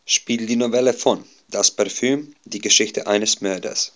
German_Speech_Data_by_Mobile_Phone_Guiding